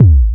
Kick_11.wav